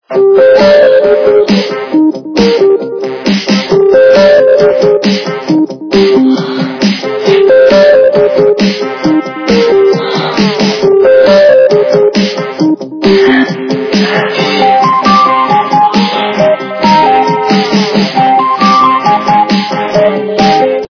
- западная эстрада
При заказе вы получаете реалтон без искажений.